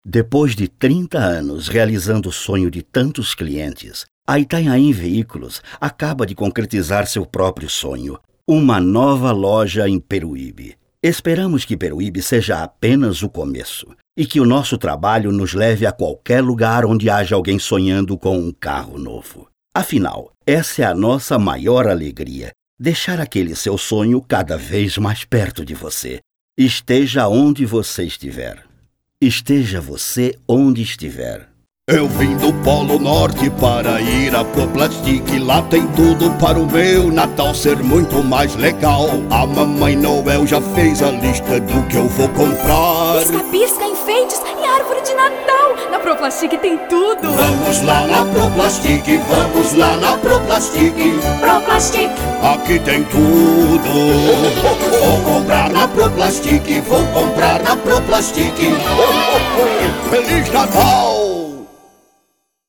Sua voz é uma das mais versáteis da dublagem brasileira, pois ele possui quatro ou mais tipos de vozes diferentes, desde a fanhosa até seu famoso vozeirão profundo.